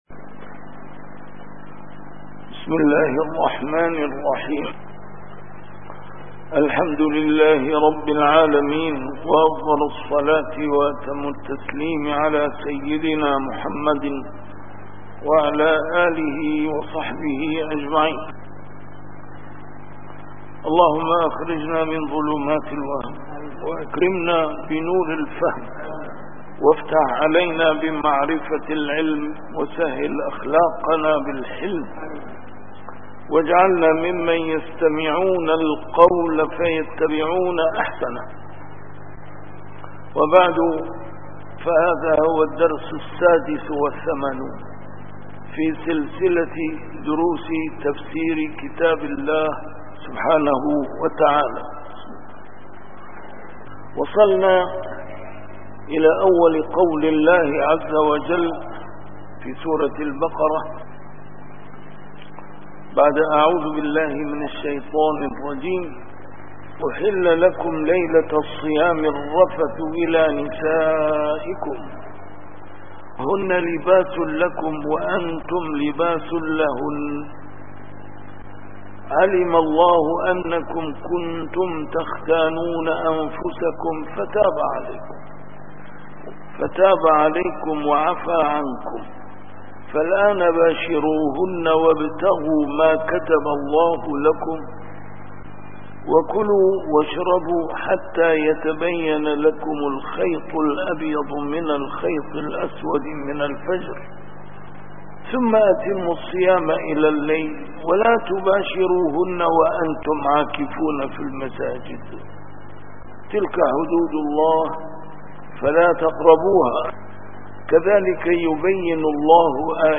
A MARTYR SCHOLAR: IMAM MUHAMMAD SAEED RAMADAN AL-BOUTI - الدروس العلمية - تفسير القرآن الكريم - تفسير القرآن الكريم / الدرس السادس والثمانون: سورة البقرة: الآية 187